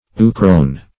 Search Result for " euchrone" : The Collaborative International Dictionary of English v.0.48: Euchrone \Eu"chrone\n. (Chem.)